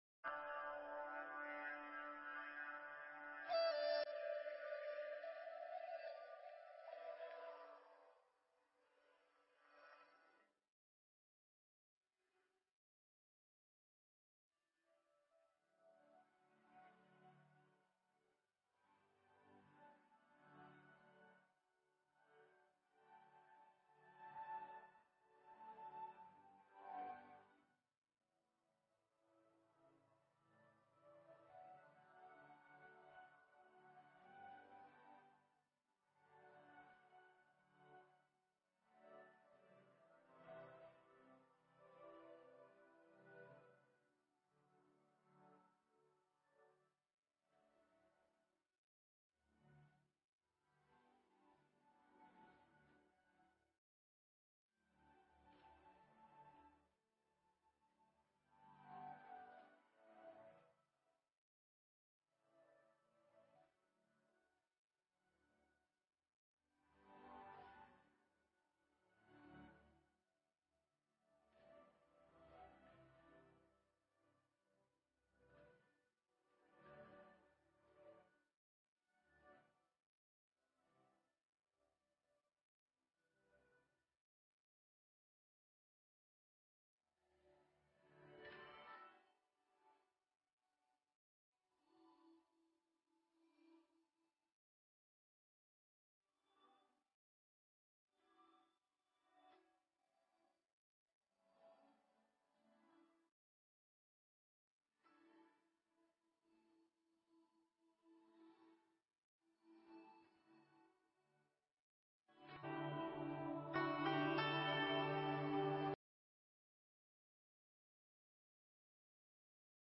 Nghe Mp3 thuyết pháp Thân Phận Con Người